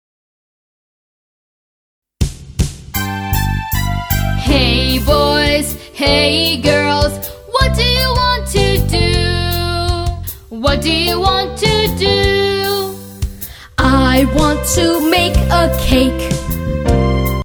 UNIT 3 SONG!